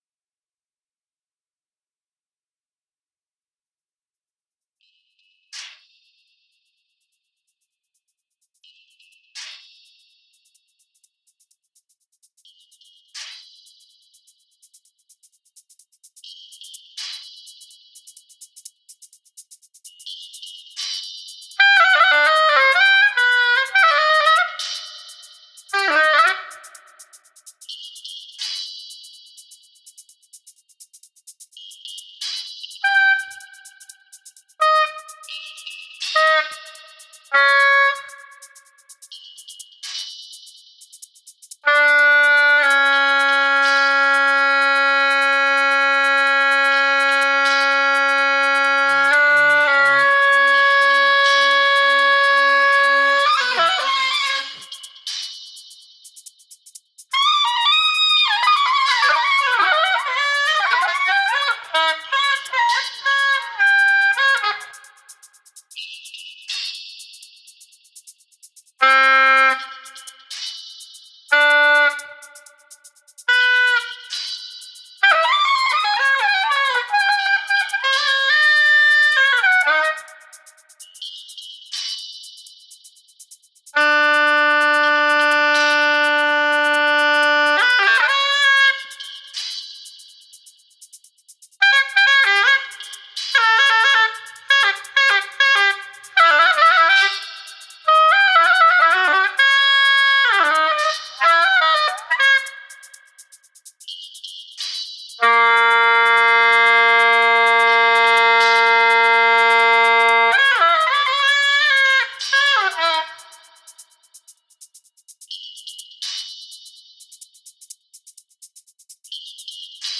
oboe
electronics and guitar